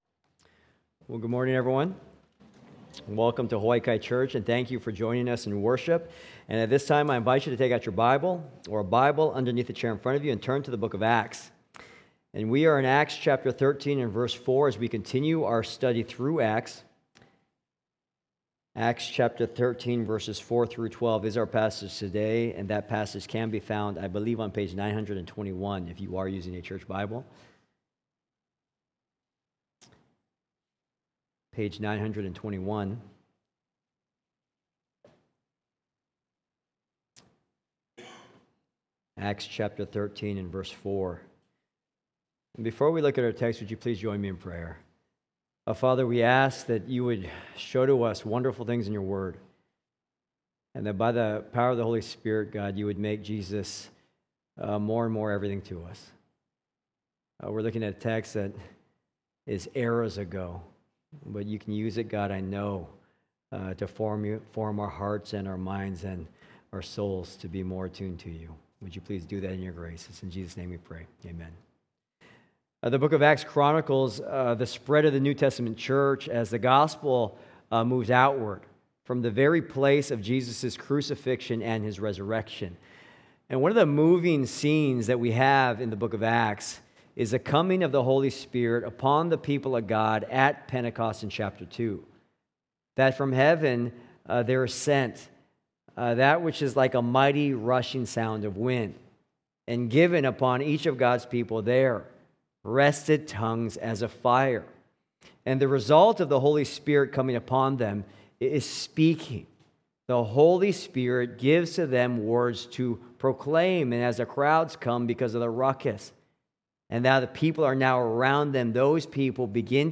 Yes, goddamnit, it’s genocide!: A conversation with Norman Solomon